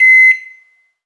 Alarms_Beeps_Siren
beep_09.wav